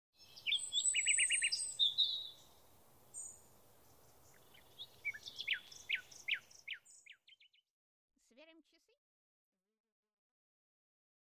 Аудиокнига Сказоньки из леса | Библиотека аудиокниг